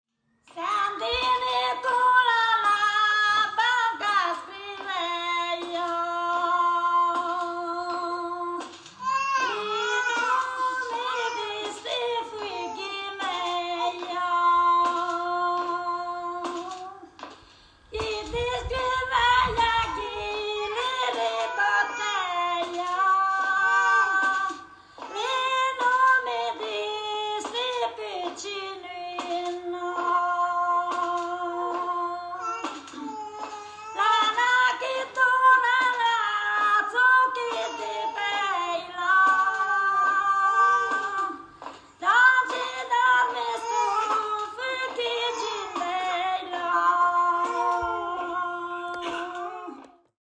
Participants reworked melodies, adapting them into new vocal forms using wheat-working tools (sieves, bamboo finger guards) as instruments, transforming traditional knowledge into sonic expression.
Methodology_ ethnographic interviews; ethnomusicological song analysis; intergenerational music workshops; participatory music performance with agricultural tools as instruments.
Excerpt of local lullaby_ethnomusicological recording